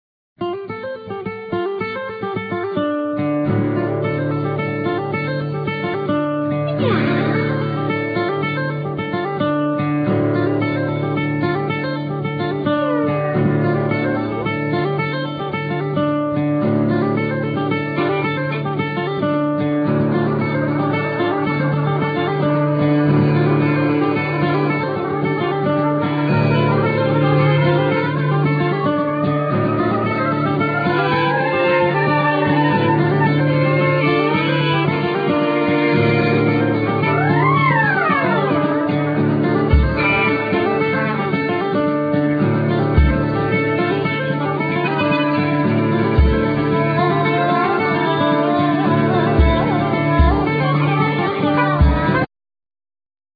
Violin,Vocal,Kazu
Ac.guitar
Trumpet
Doublebass
Drums,Percussions